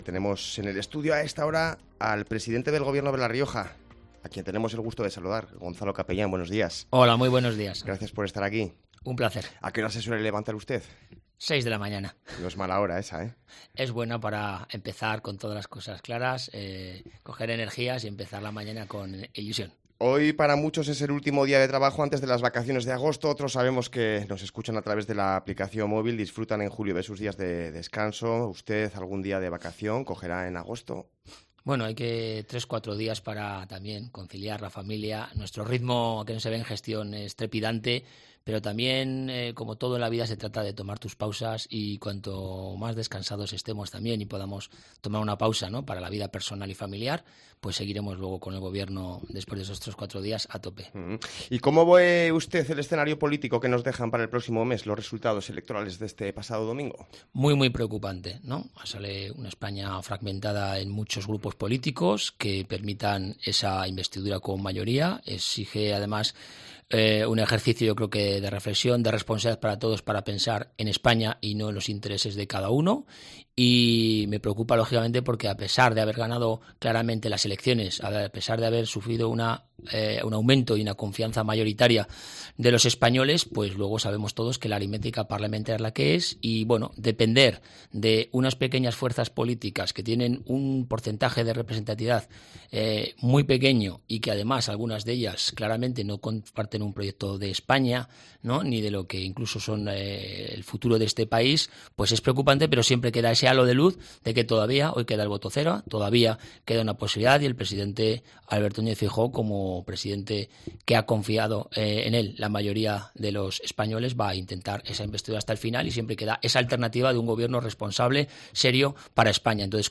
Escucha la entrevista en COPE Rioja al presidente del Gobierno de La Rioja, Gonzalo Capellán